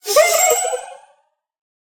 Minecraft Version Minecraft Version 25w18a Latest Release | Latest Snapshot 25w18a / assets / minecraft / sounds / mob / allay / idle_with_item2.ogg Compare With Compare With Latest Release | Latest Snapshot